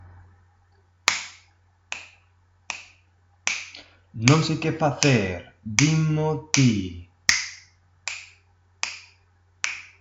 Agora, é momento de escoitar como quedaría este mesmo esquema rítmico se lle engadimos a letra que acabamos de ver nos dous exemplos.